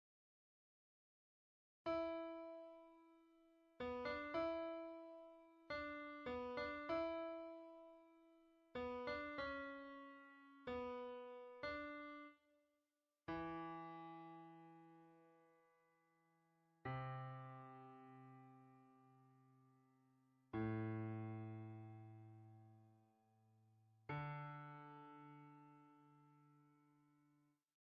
Piano Synthesia Tutorial